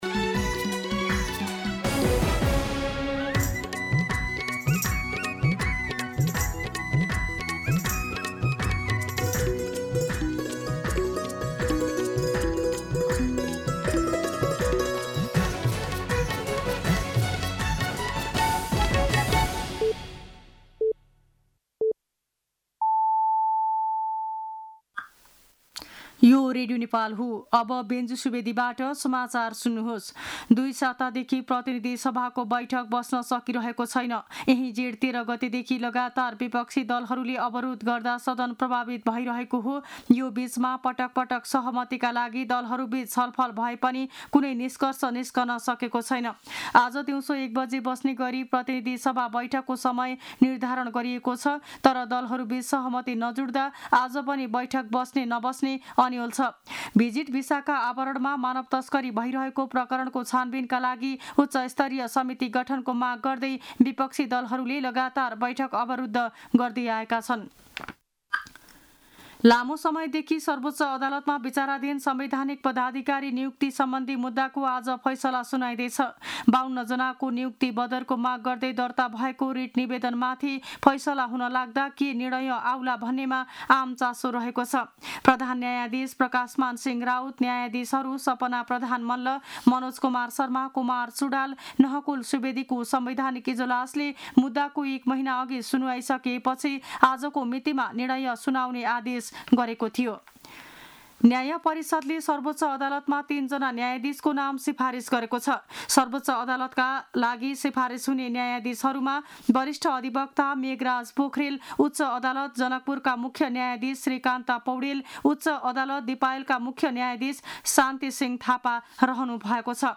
मध्यान्ह १२ बजेको नेपाली समाचार : २८ जेठ , २०८२